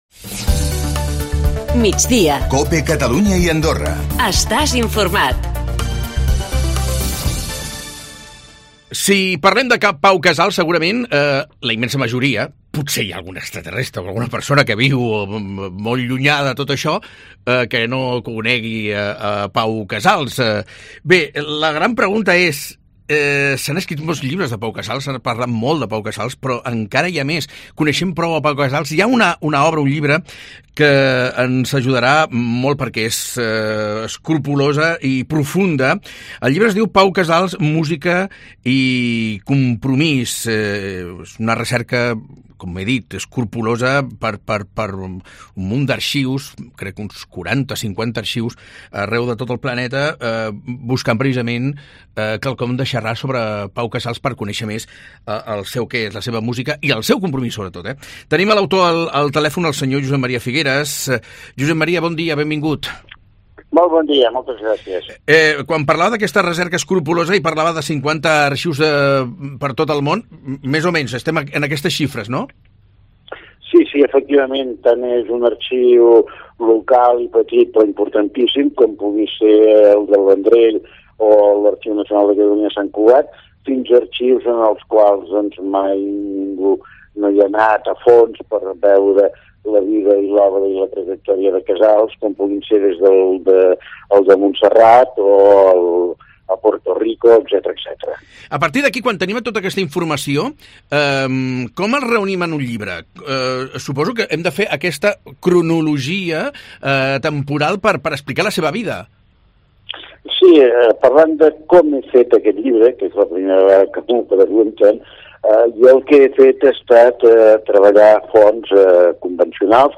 a Migdia COPE Catalunya (àudio).